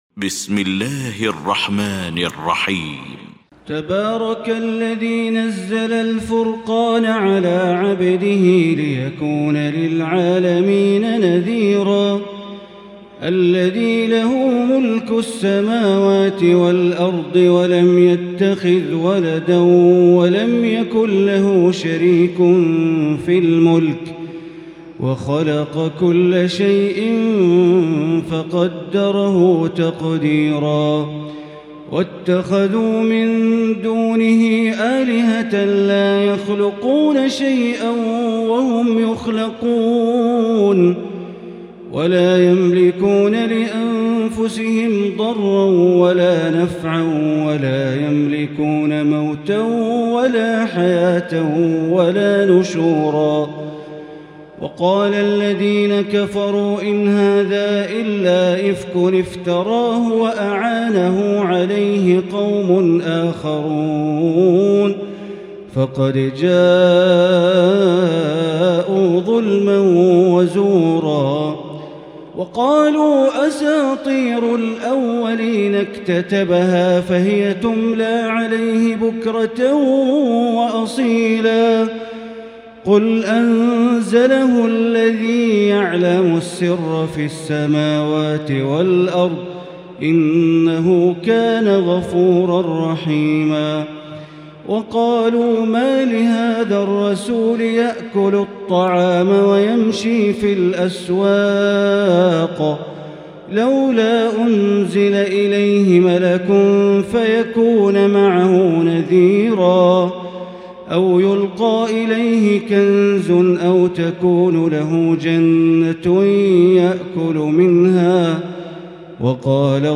المكان: المسجد الحرام الشيخ: معالي الشيخ أ.د. بندر بليلة معالي الشيخ أ.د. بندر بليلة الفرقان